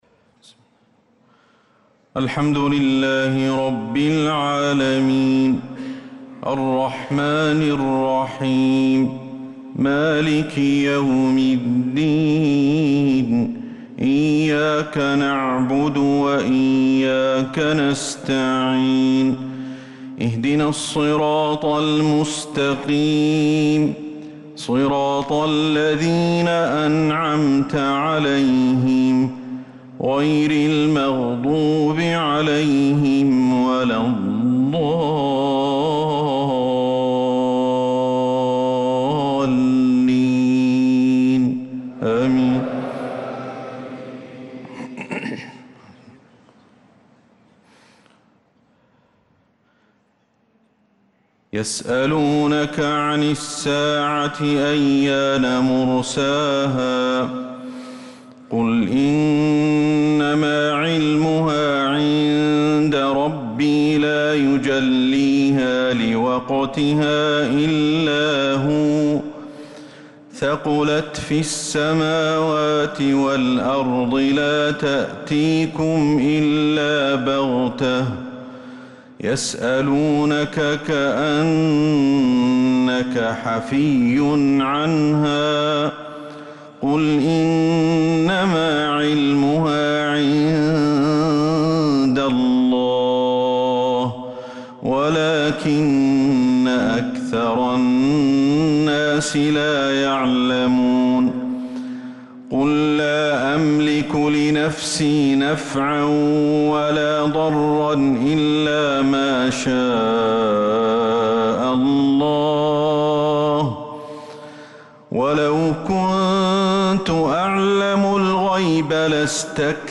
صلاة العشاء للقارئ أحمد الحذيفي 29 ربيع الآخر 1446 هـ
تِلَاوَات الْحَرَمَيْن .